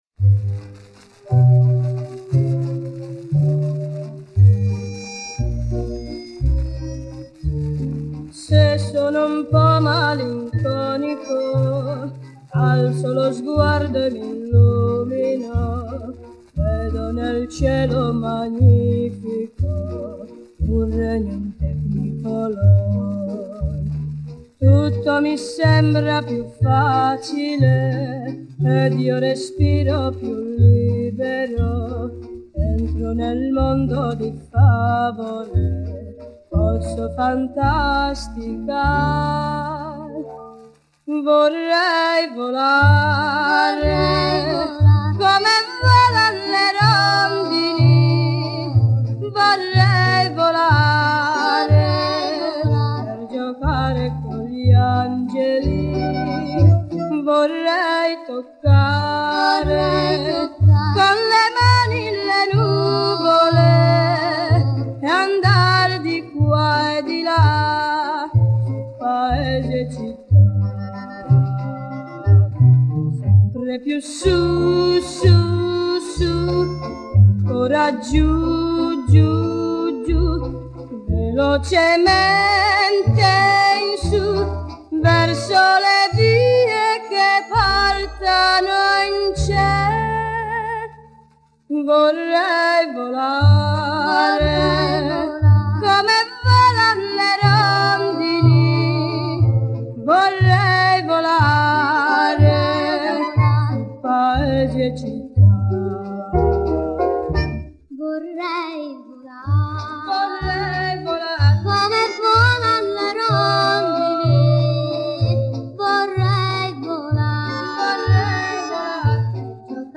И битрейт сохранили и ужасный шум убрали.